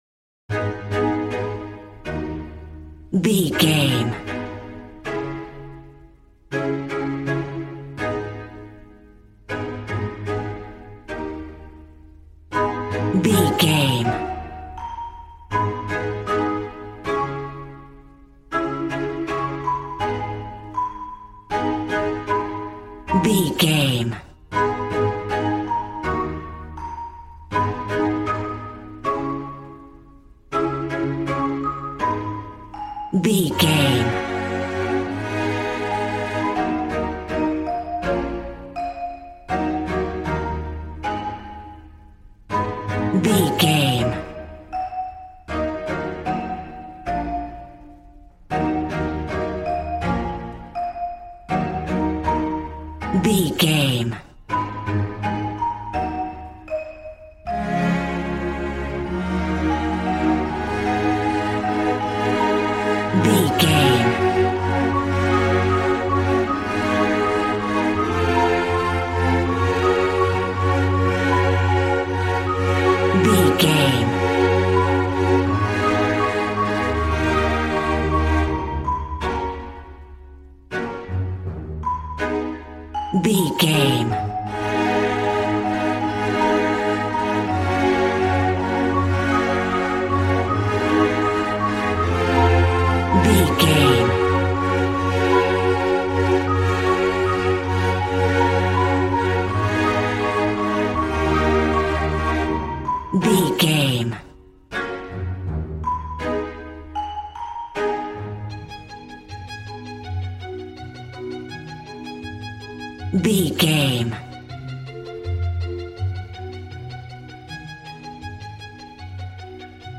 Aeolian/Minor
A♭
positive
cheerful/happy
joyful
drums
acoustic guitar